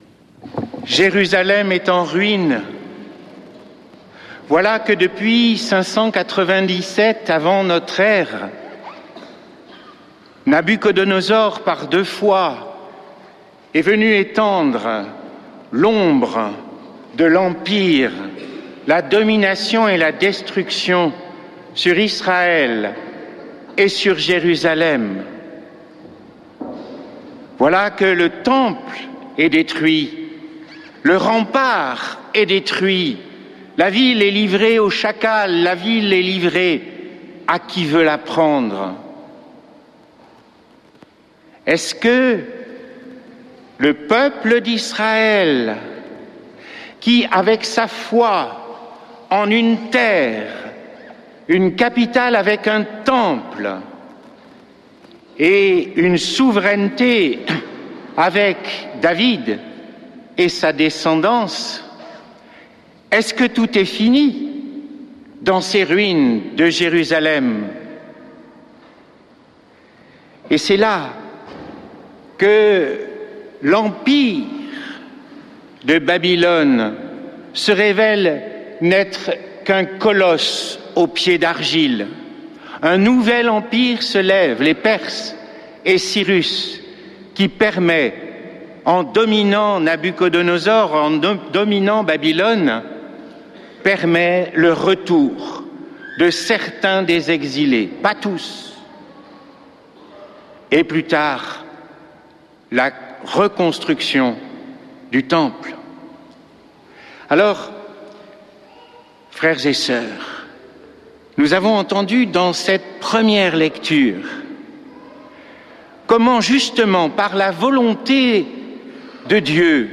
Messe depuis le couvent des Dominicains de Toulouse